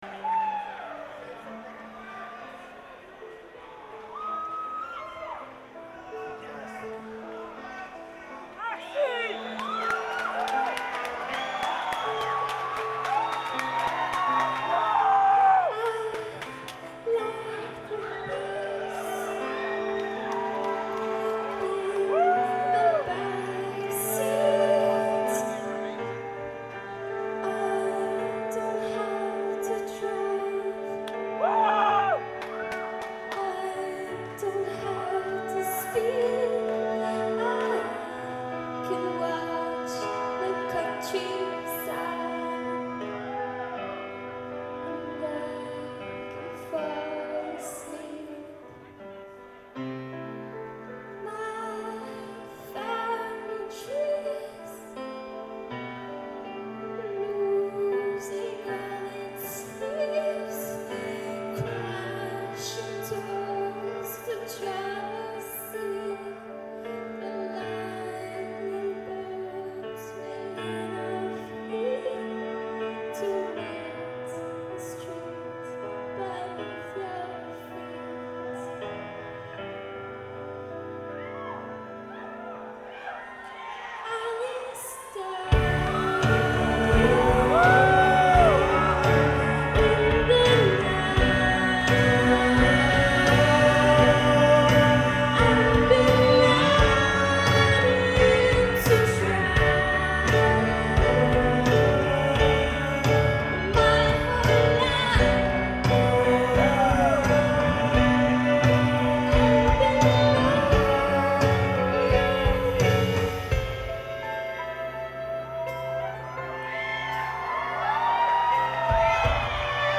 Live at the Orpheum
in Boston, Massachusetts